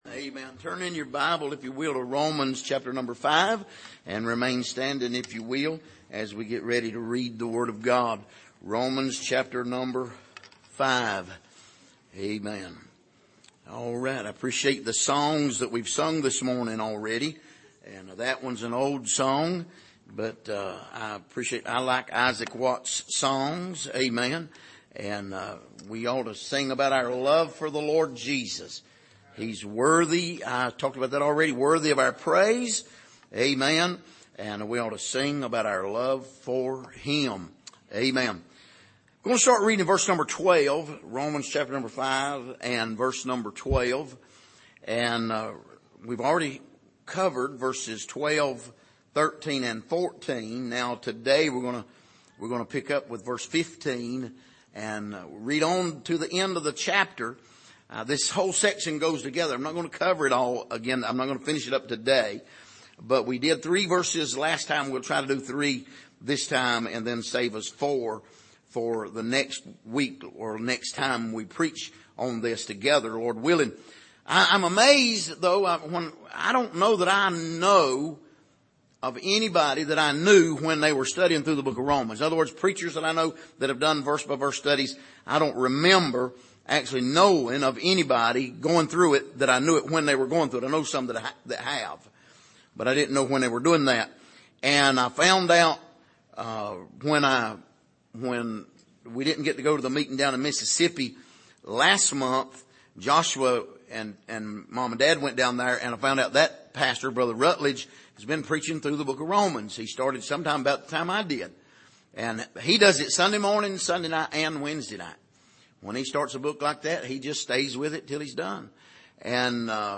Passage: Romans 5:15-17 Service: Sunday Morning